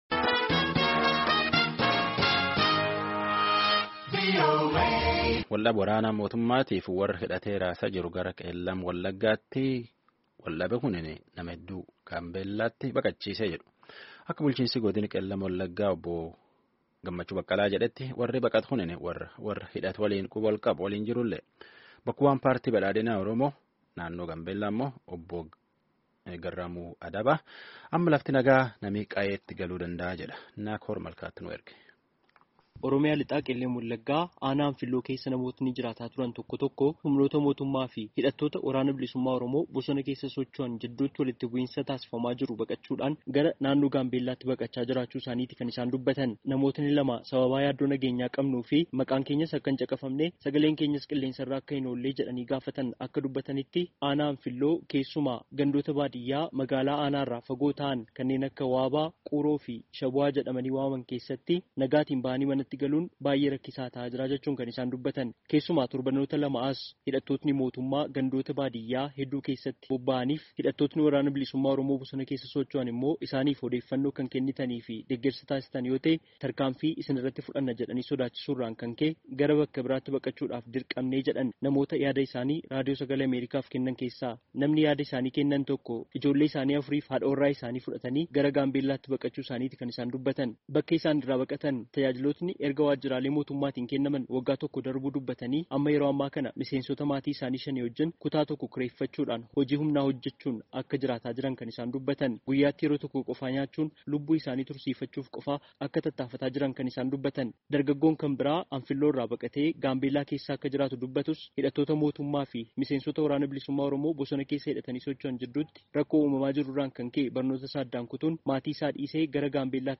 Gabaasaa guutuu caqasaa.